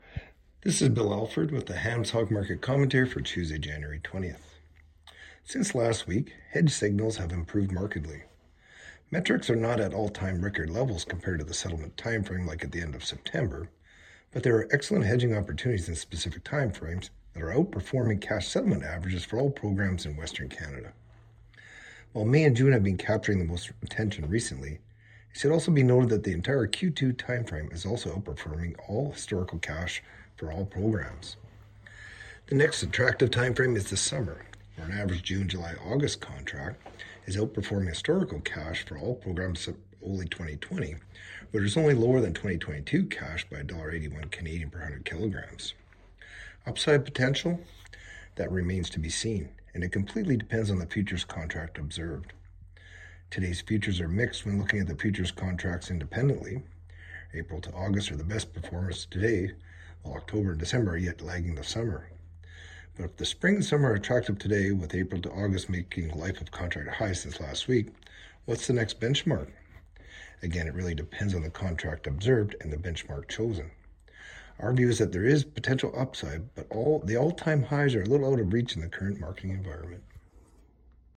Hog-Market-Commentary-Jan.-20-26.mp3